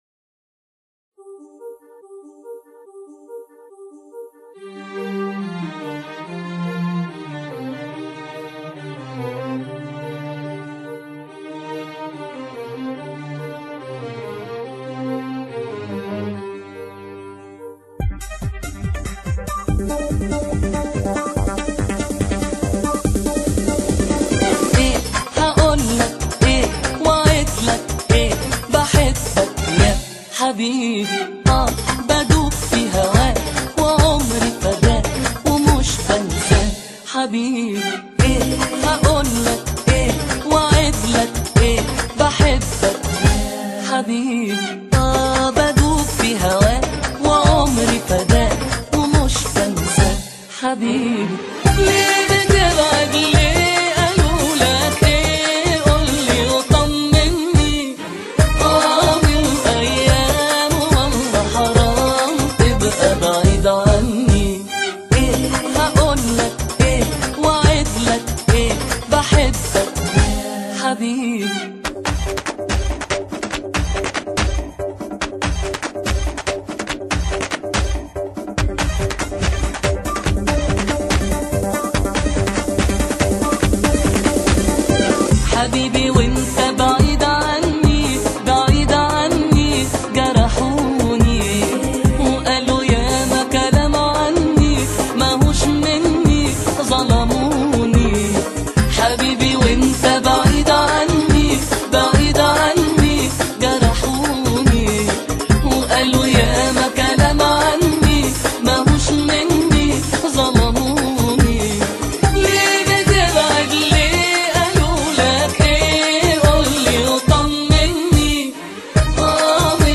Foiz__neizvestnyy_ispolnitel__ochen_dinamichnyy_vostochnyy_tanec.mp3